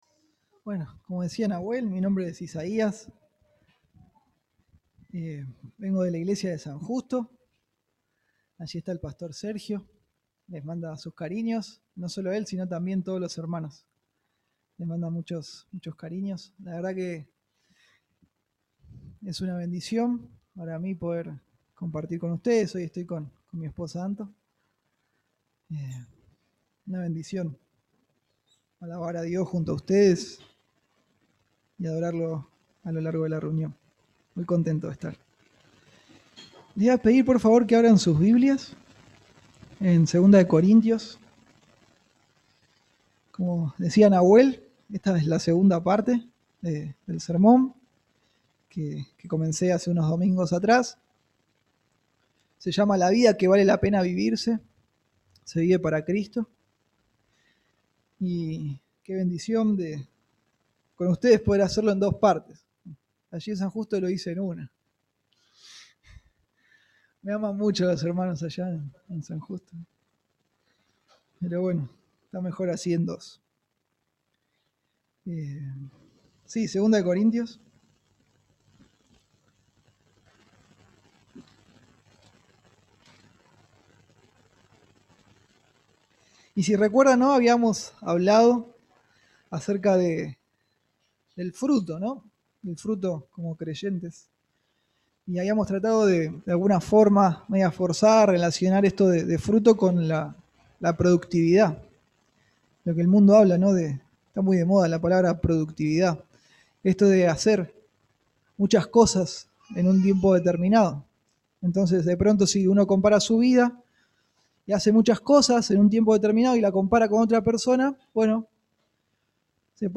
Una vida que vale la pena vivirse se vive para servir a otros para la gloria de Dios. Un sermón basado en 1 Corintios 6:14 al 16:24